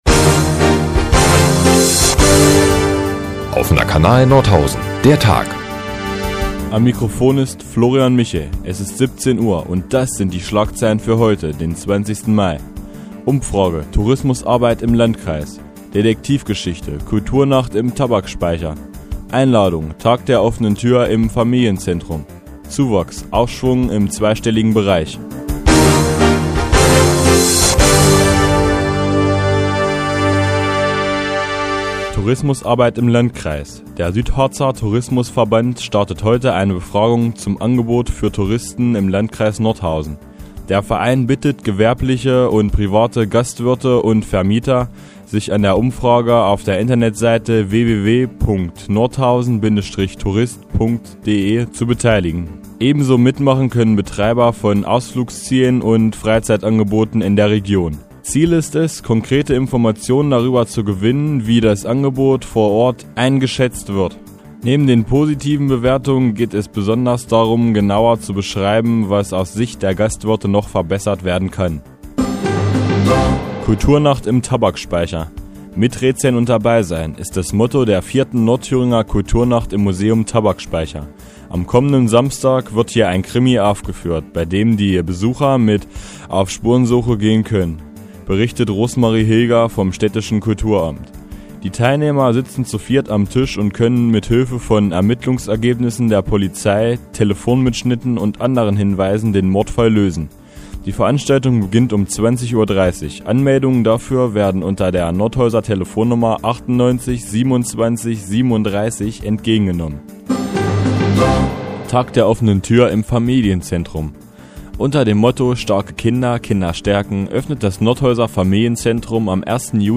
Die tägliche Nachrichtensendung des OKN ist nun auch hier zu hören.